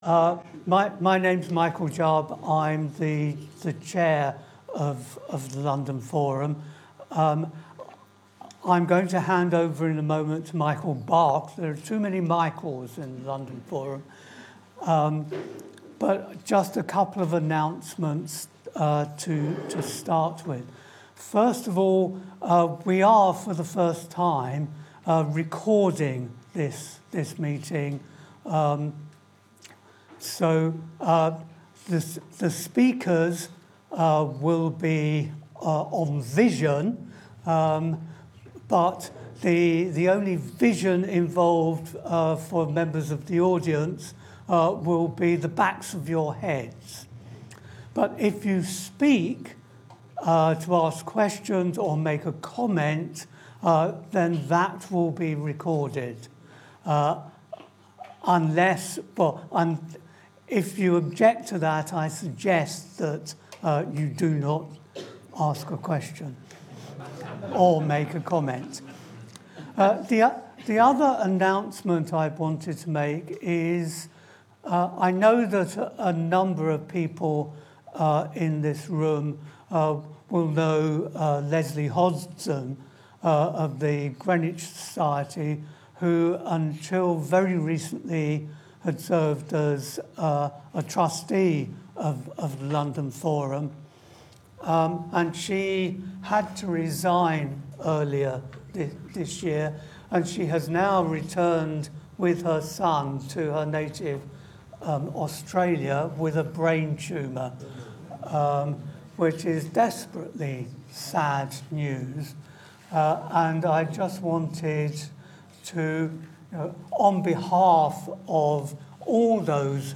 Meeting Record